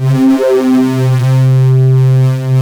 OSCAR 10 C3.wav